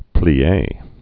(plē-ā)